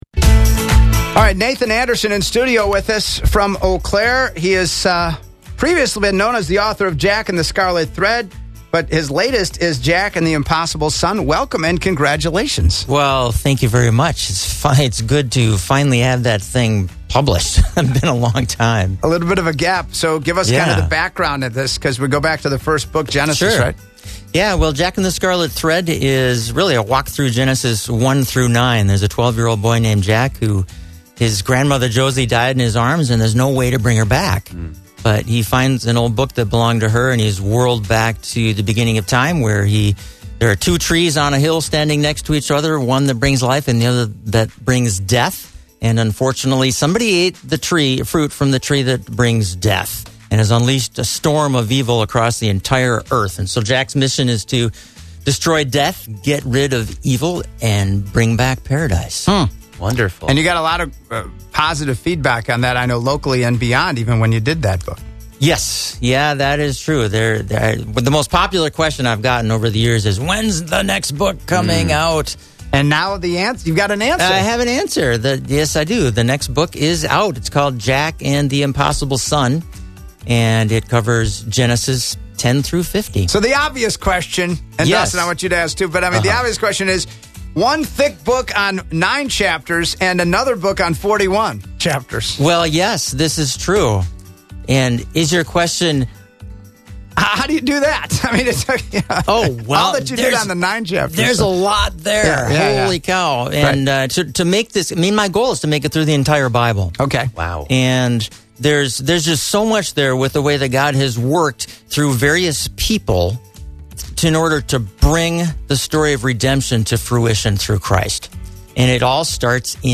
Interview on the WWIB Wake-Up Show